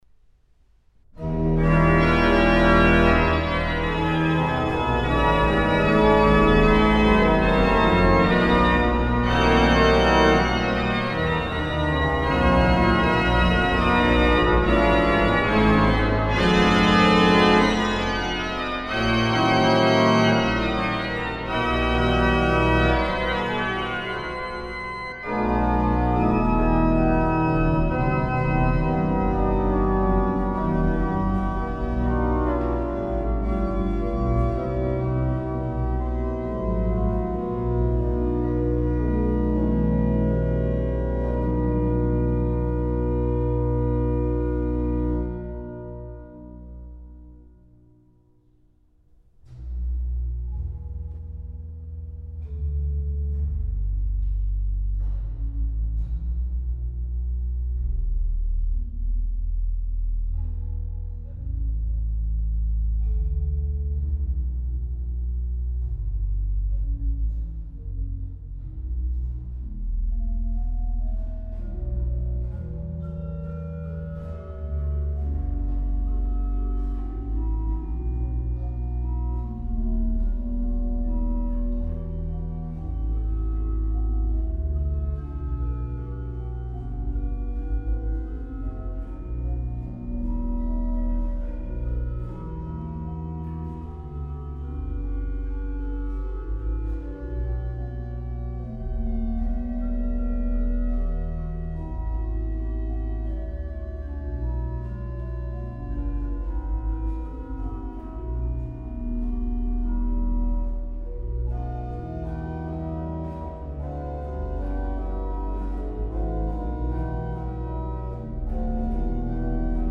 Rheinberger Passacaglia  uit sonate nr. 8. gespeeld op het Timpe-orgel (1818) van de St.Janskerk te Zutphen.